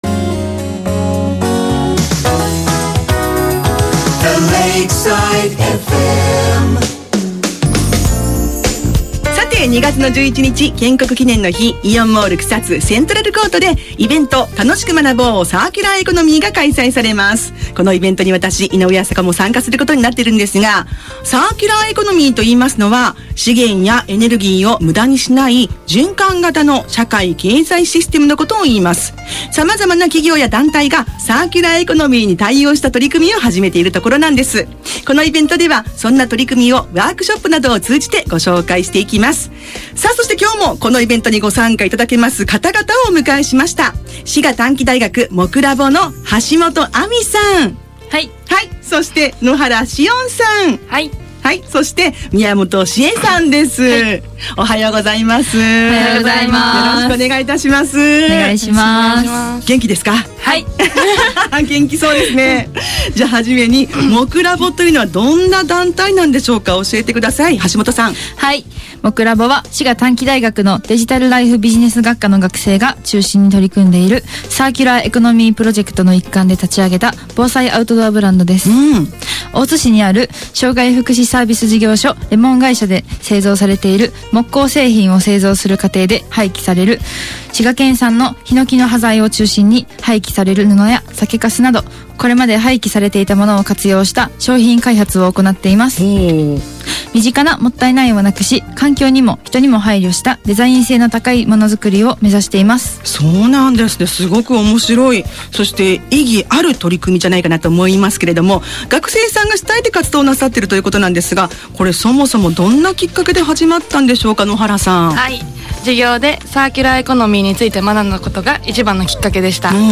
１月３０日（金）、本学の学生が出演した e-radio のラジオ番組「LIFE！」が放送されました。